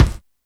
kits/RZA/Kicks/WTC_kYk (69).wav at main